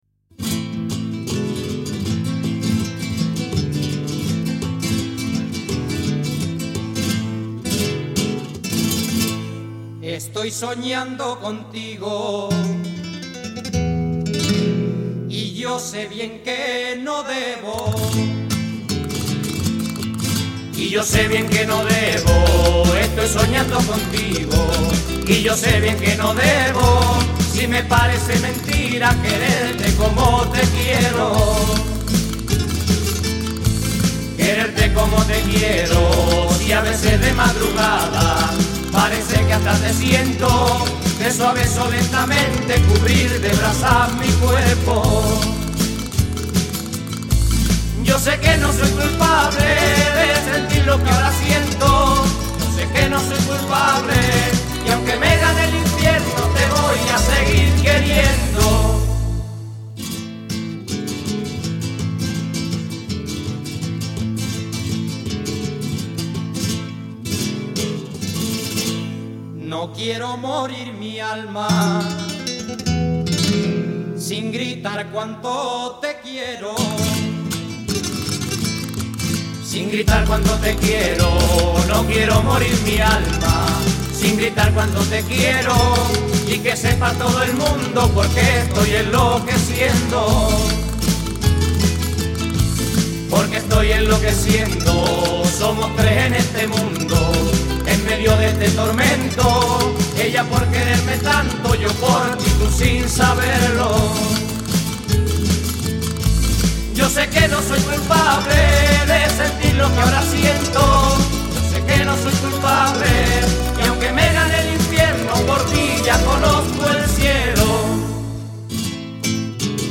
Sevillana